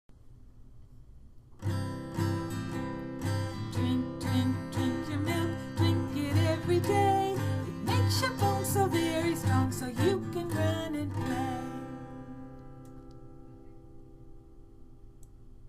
DOMAIN(S) Physical Development and Motor Skills (PDM) INDICATOR(S) PDM1.4e Tune: “Row, Row, Row Your Boat” Drink, drink, drink your milk Drink it every day It makes your bones so very strong So you can run and play